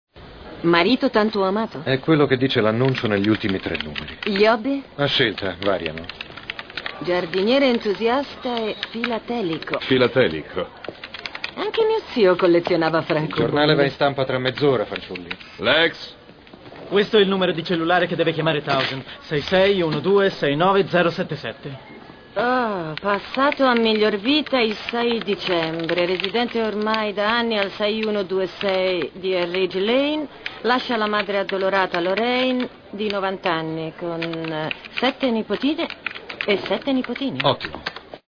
nel telefilm "L'Agenzia", in cui doppia Paige Turco.